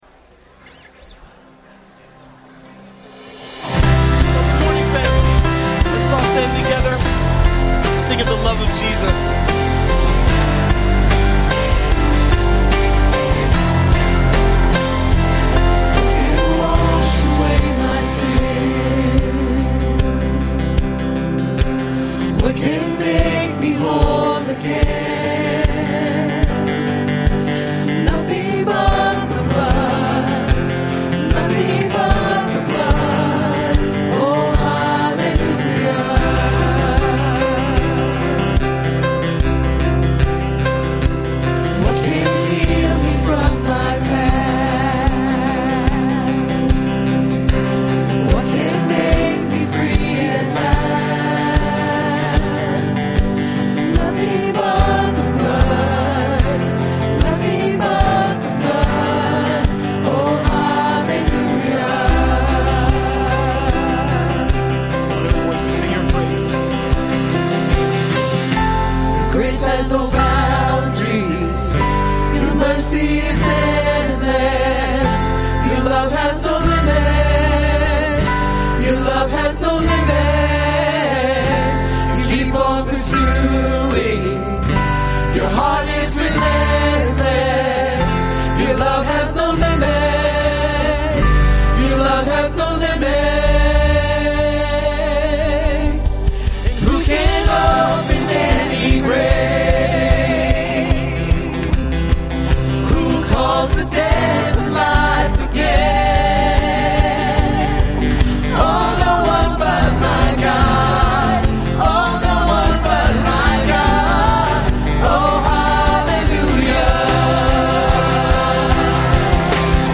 A message from the series "Ask the Pastors."